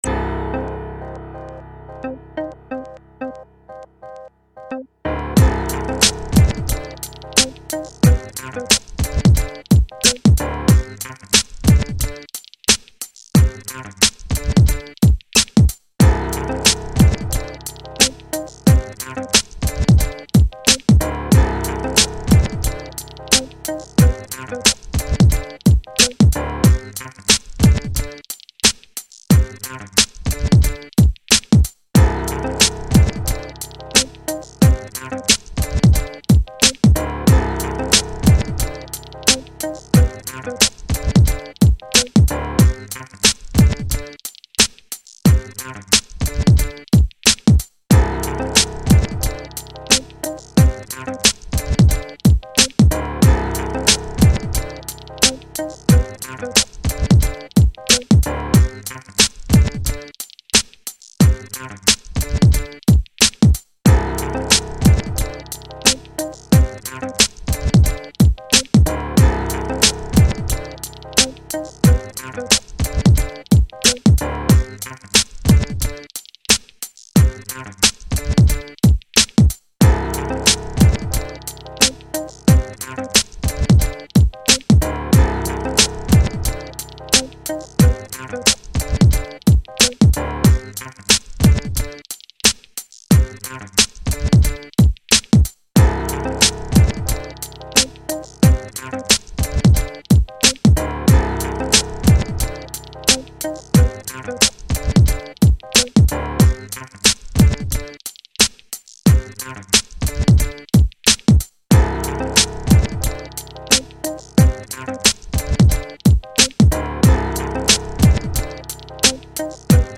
gangstaRAPP.mp3